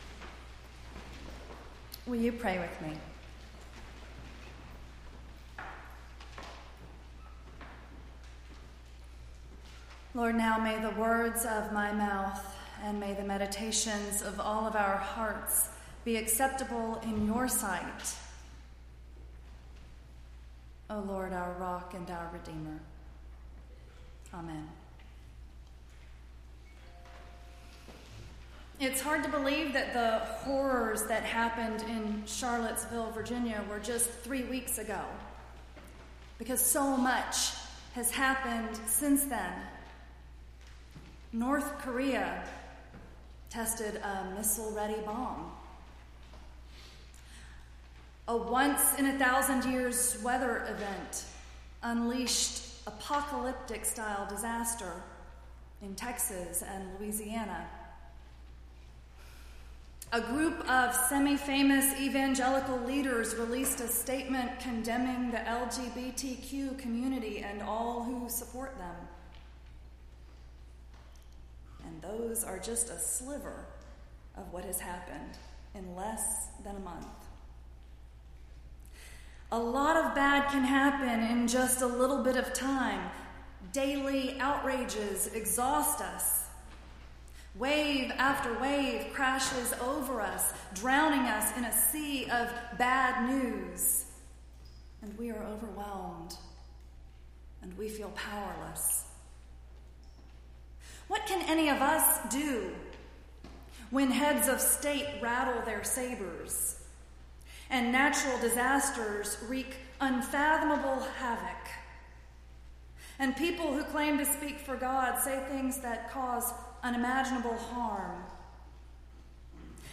9-3-17-sermon.mp3